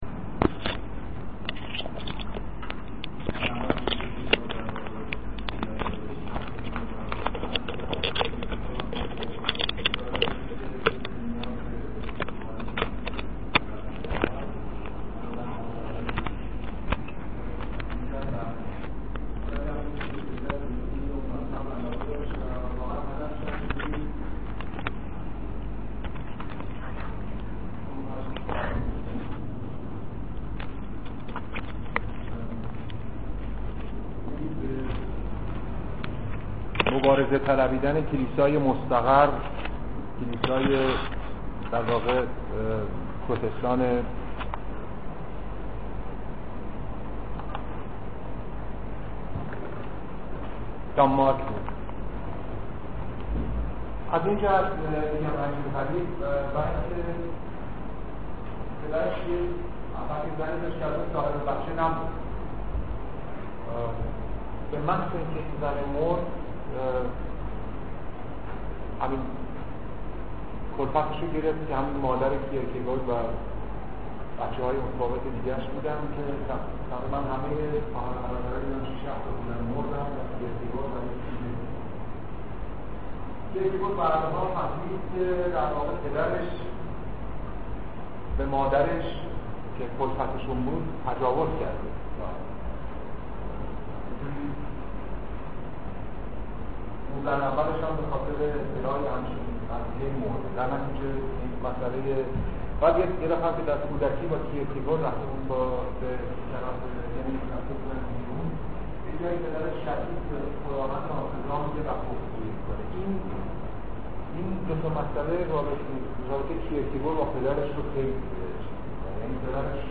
فرهنگ امروز: فایل زیر درسگفتار دکتر «یوسف اباذری» دربارۀ سورن کی یر کگور است.